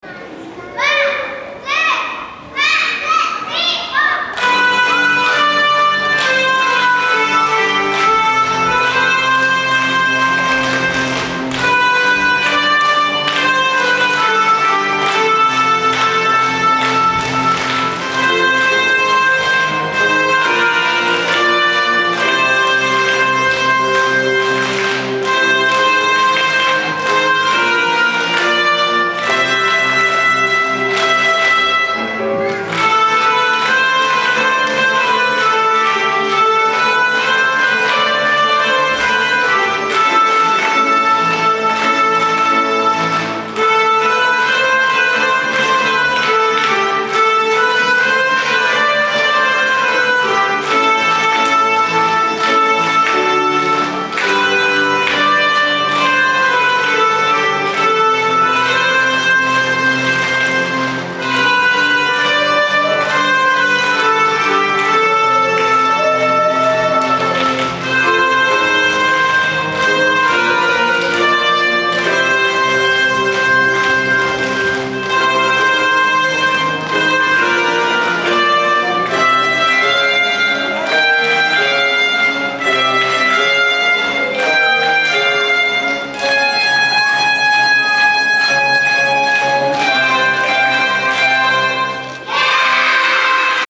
２年生は鍵盤ハーモニカに、１年生はクラッピングにチャレンジ!!
２年生のカウント「ワン・ツー・ワン・ツー・スリー・フォー！！」で、元気いっぱいの演奏がスタート♪
２年生がメロディーで１年生をリードし、１年生はクラスごとに違うリズムで演奏しました♪